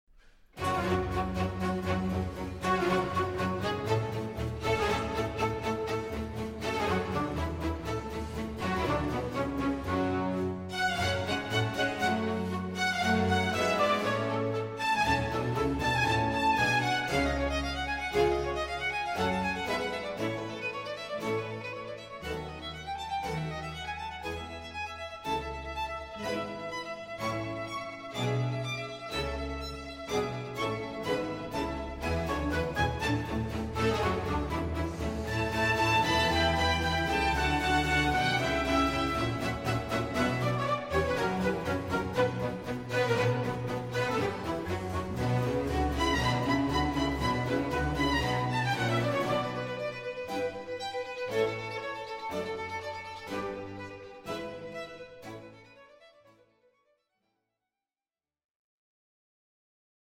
baroque repertoire